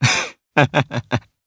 Kibera-Vox-Laugh_jp.wav